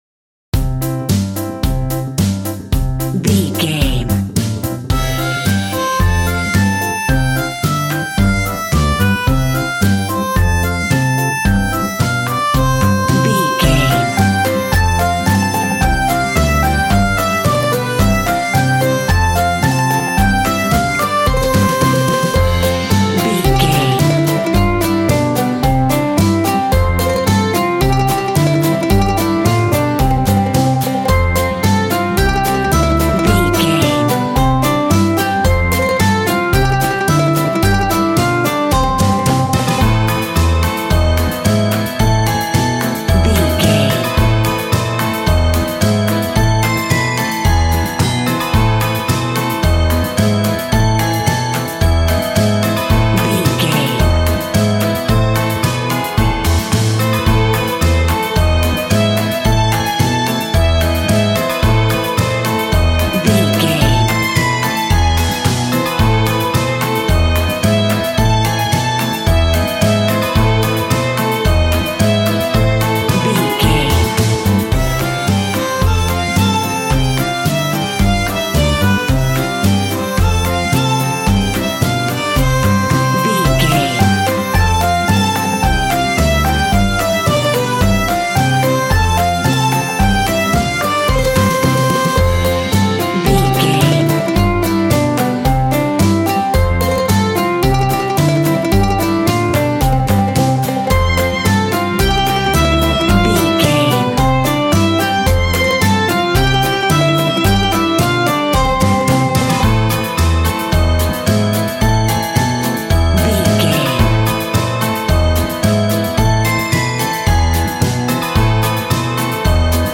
Ionian/Major
cheerful/happy
bouncy
electric piano
electric guitar
drum machine